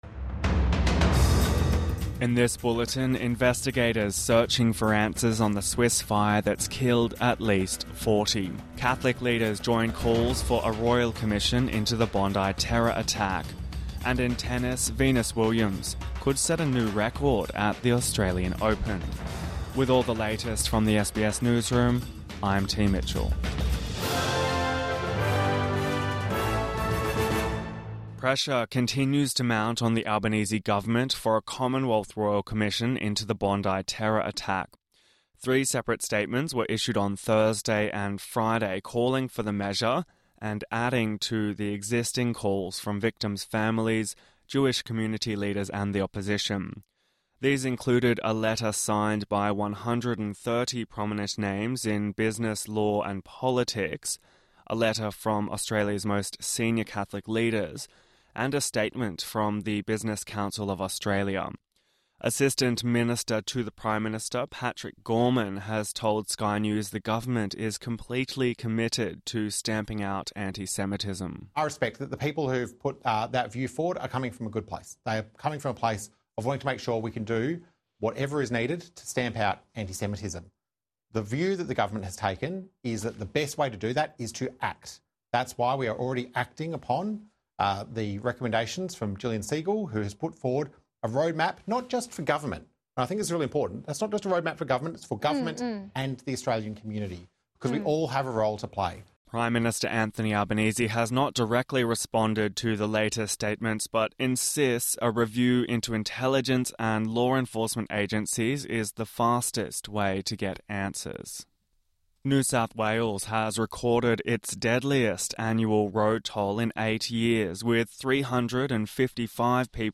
Search for answers after deadly Swiss fire | Morning Bulletin 03 January 2026